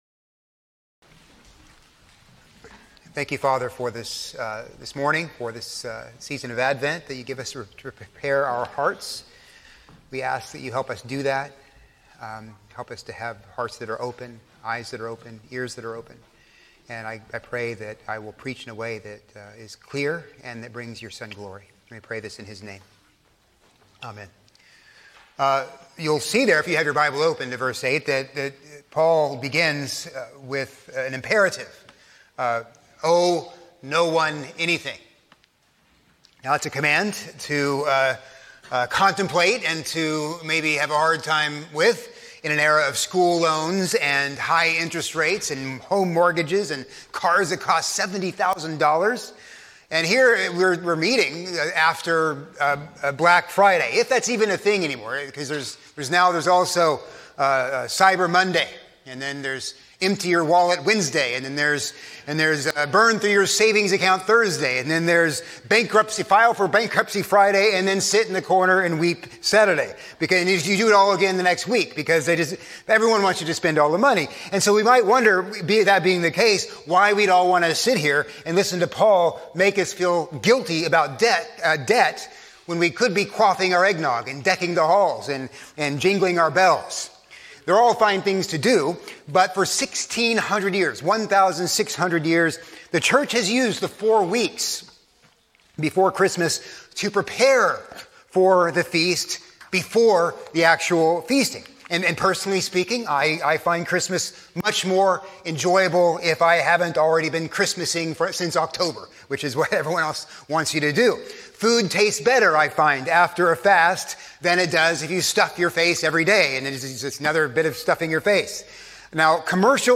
A sermon on Romans 13:8-14by